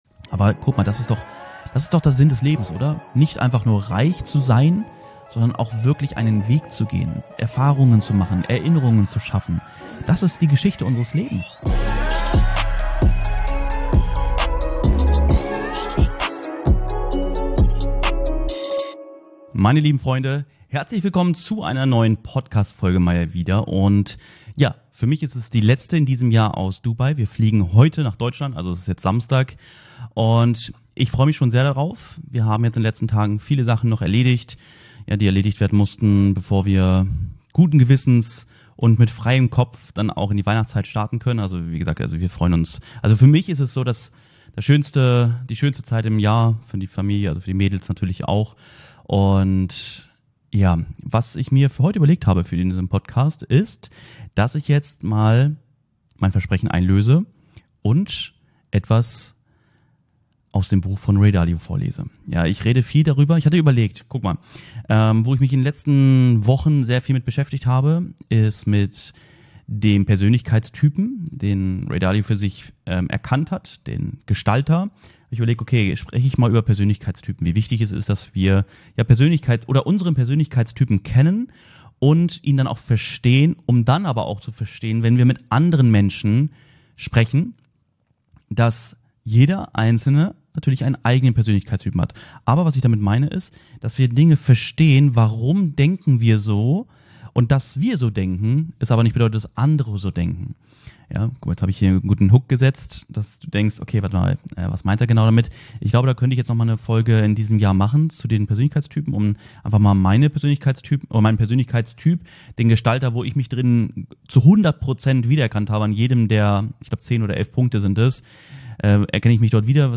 In dieser Folge lese ich euch, aus dem Buch "Prinzipien des Erfolgs" von Ray Dalio, hochspannende Ansichten und Learnings vor.